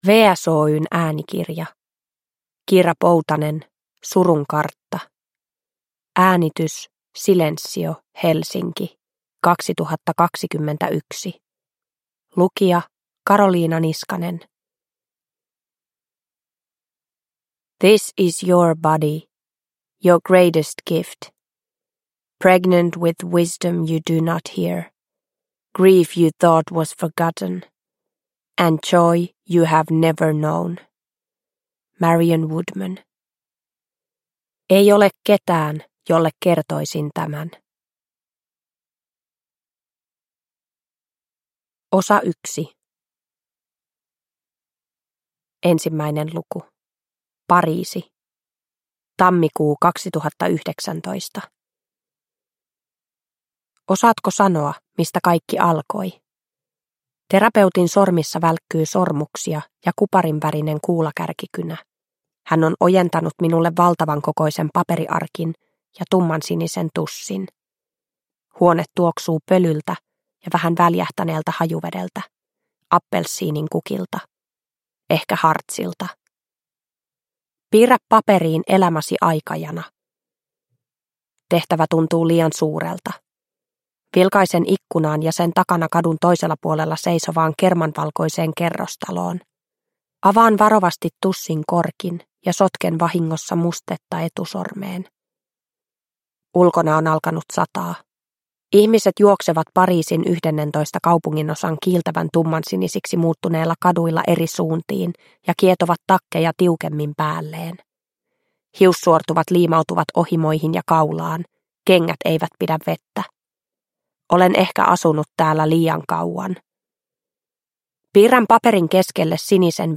Surun kartta (ljudbok) av Kira Poutanen